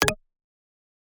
pda_beep_1.ogg